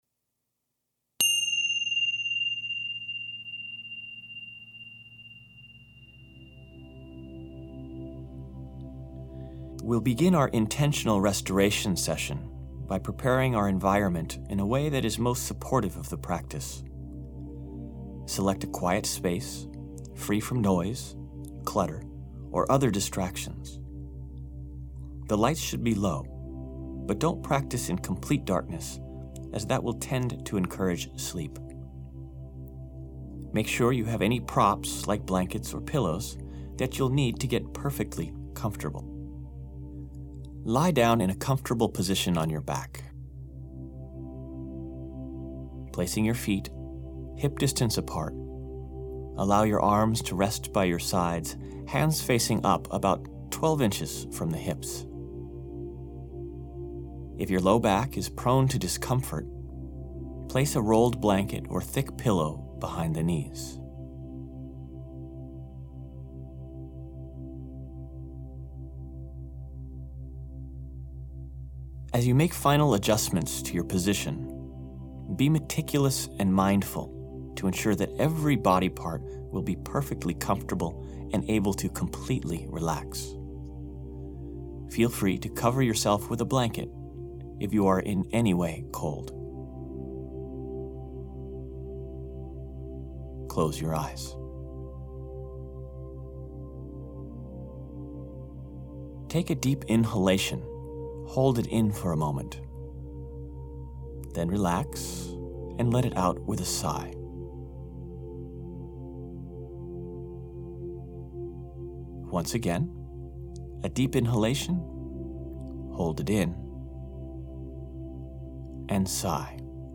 CUSTOM YOGA NIDRA SESSION Enjoy this 30-minute, yoga nidra based, relaxation session designed especially for chronic stress.